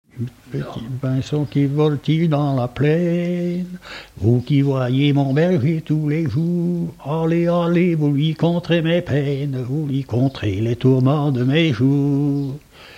Givrand
Genre laisse
Pièce musicale inédite